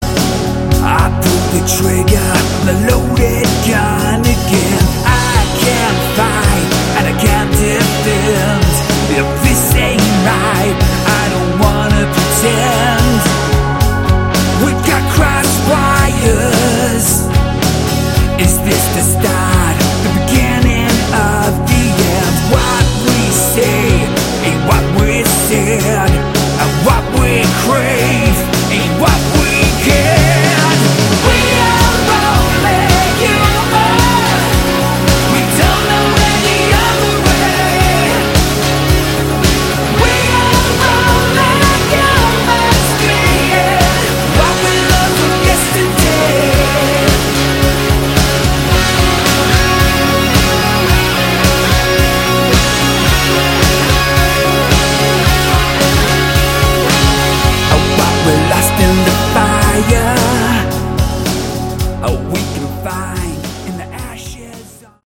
Category: Melodic Rock
keyboards
lead guitar
drums
guitar, backing vocals
lead & backing vocals, acoustic guitar
bass